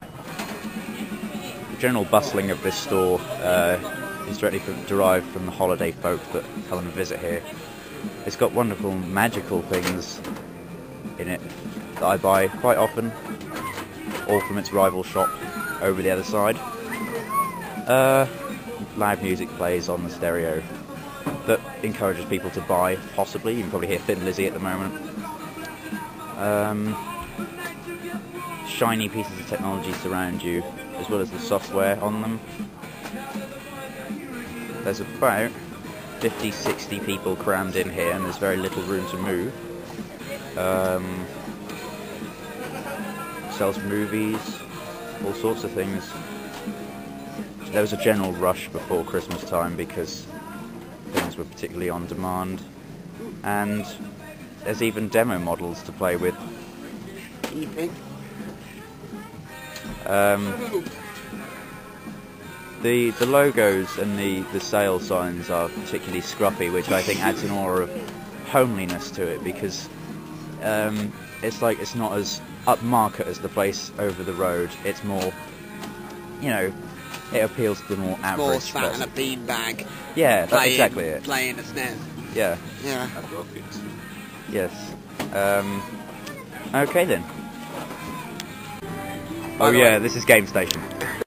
Audio tour of Gamestation